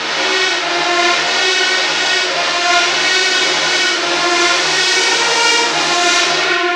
Reverb Tails
SJ-Reverb-Tail-10-Root-Note-D-sharp.wav